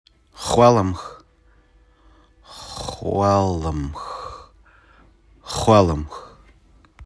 Learn how to pronounce xgwélemc (fox) with this